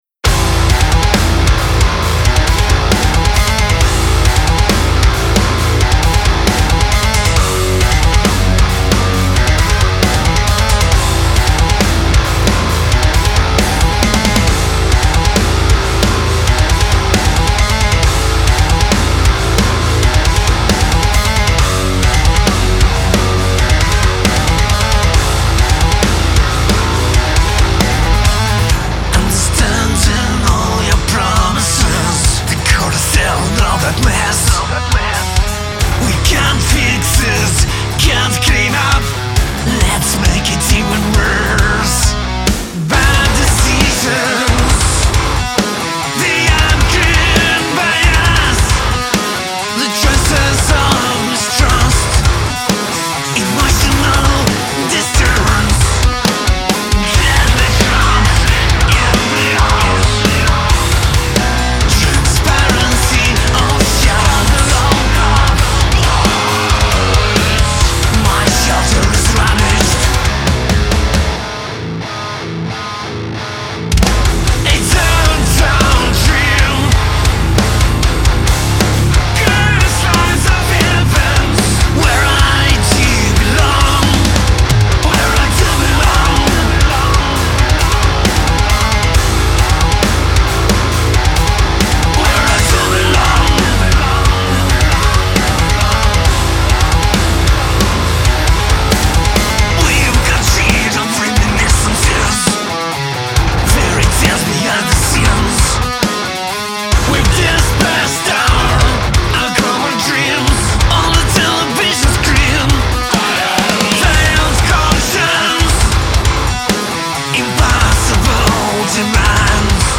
progressive industrial metal